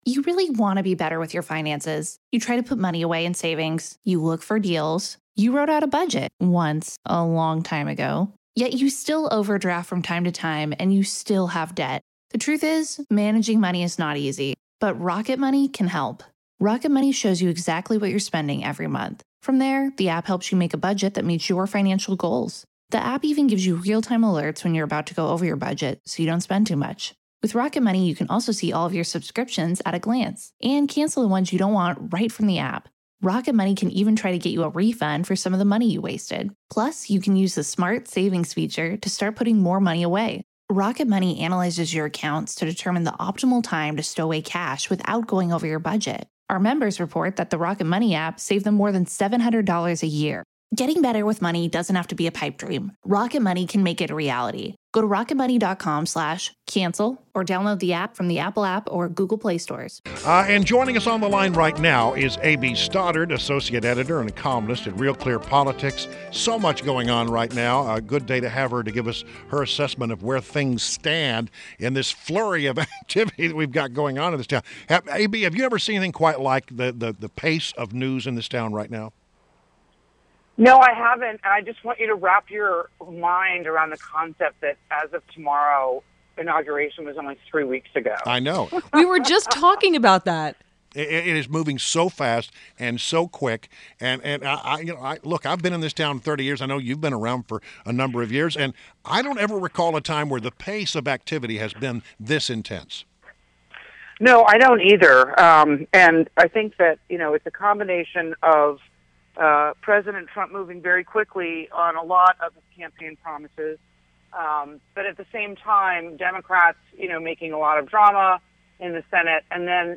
INTERVIEW – AB STODDARD – associate editor and columnist at RealClearPolitics